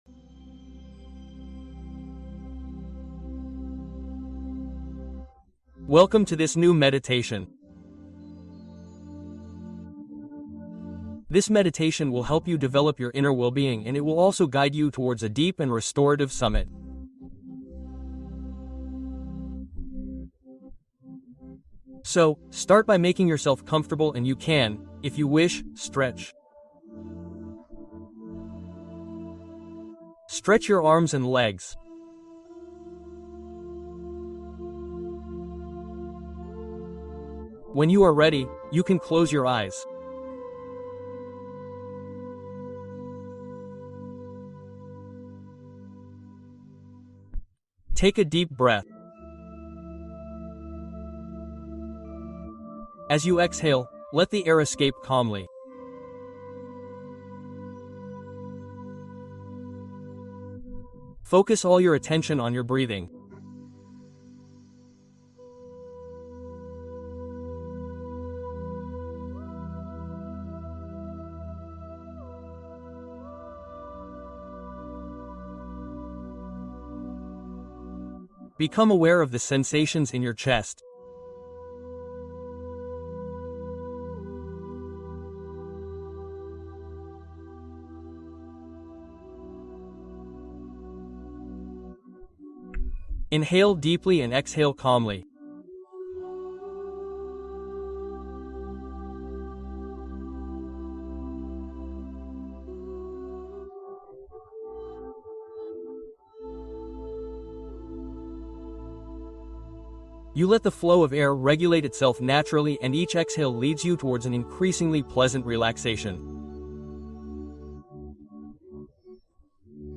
Nuit de Changement : Hypnose nocturne pour un sommeil de qualité